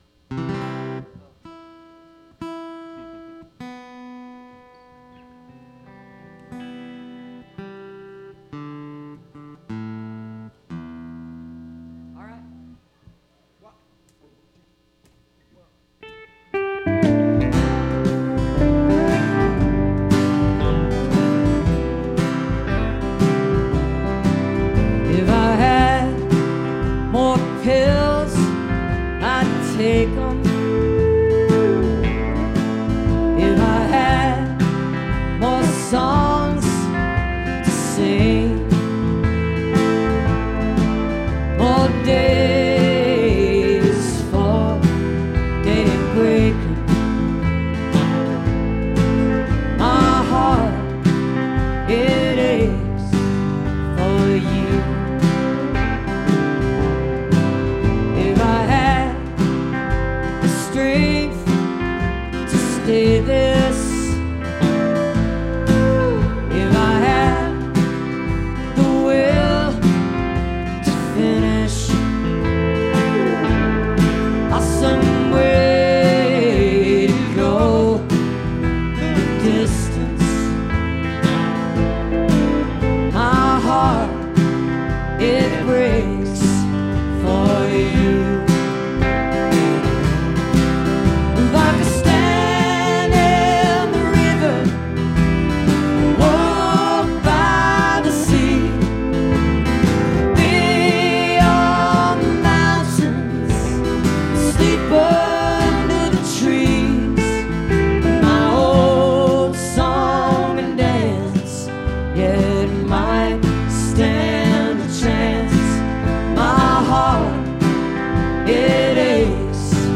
(recorded from webcast)